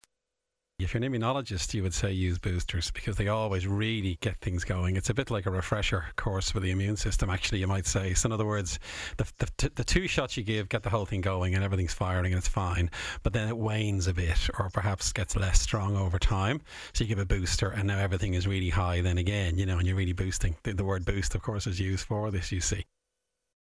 Immunologist Professor Luke O’Neill says attention needs to turn to booster shots next: